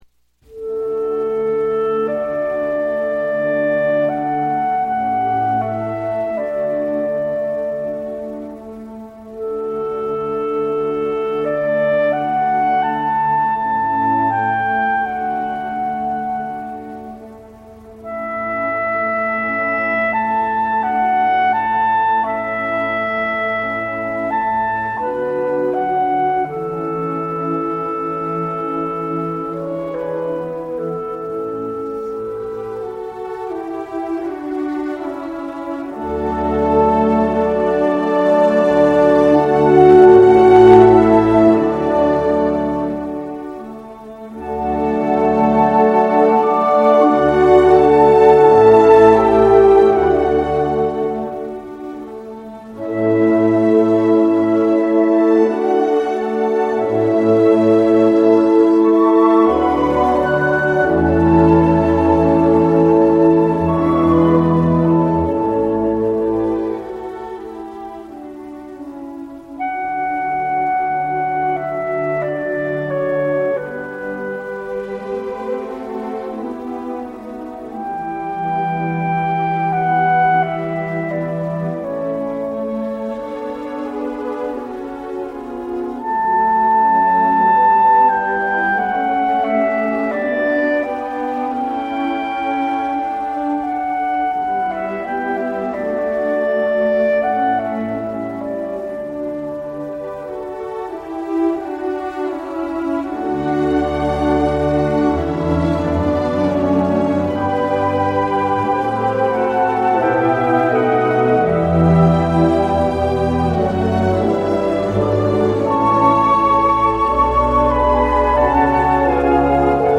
Classic
클라리넷과 오케스트라를 위한 모차르트 협주곡 A장조